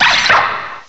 cry_not_axew.aif